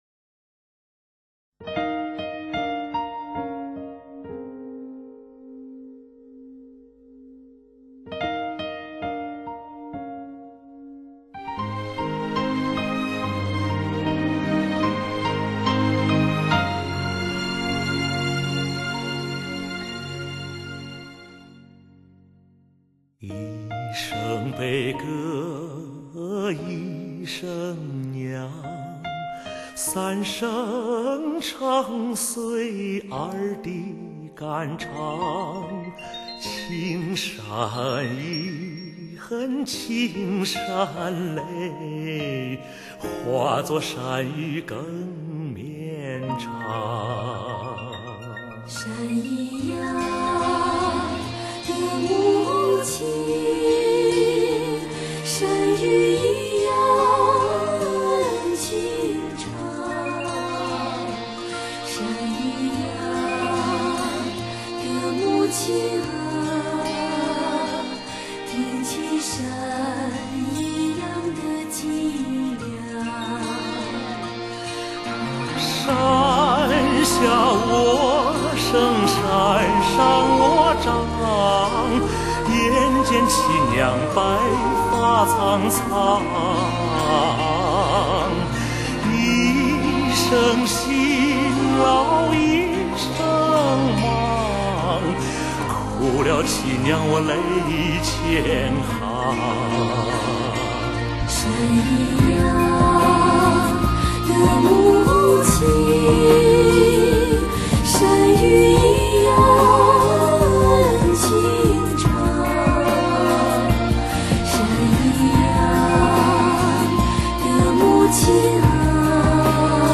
片尾曲